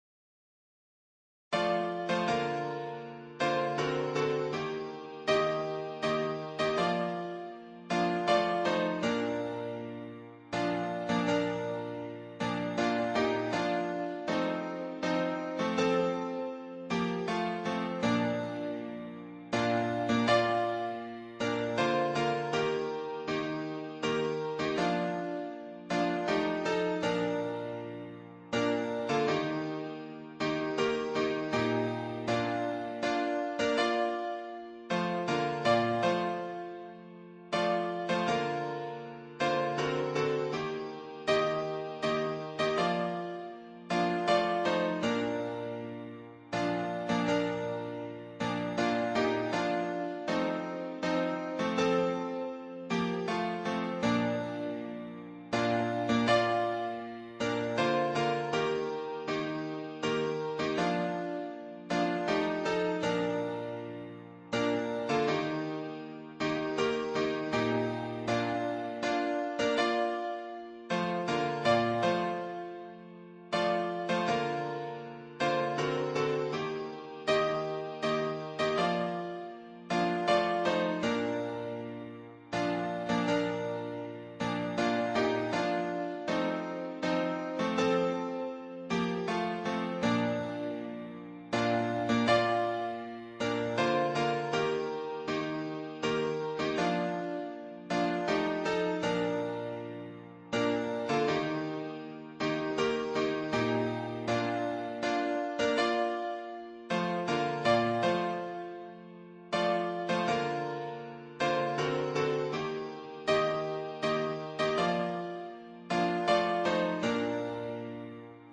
原唱音频